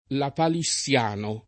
lapalissiano [ lapali SSL# no ] agg.